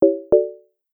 complete.oga